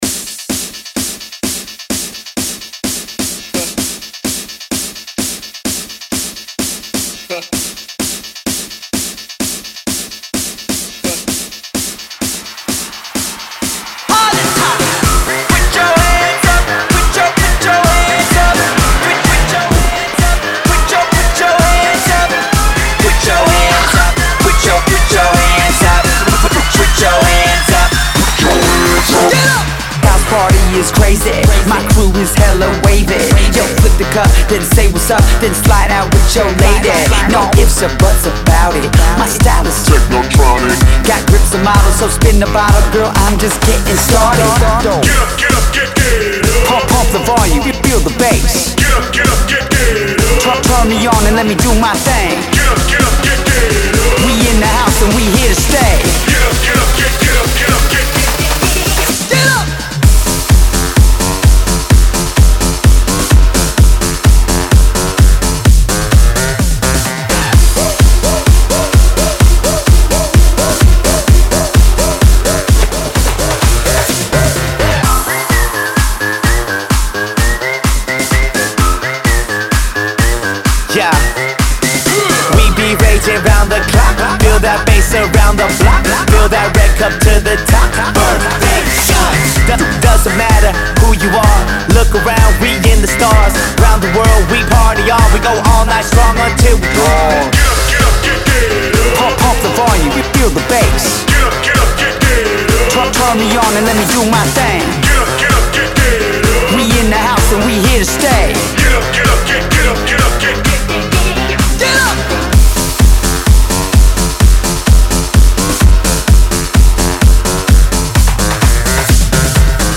BPM: 128 Time